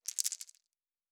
Dice Shake 1.wav